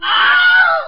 Wilhelm Scream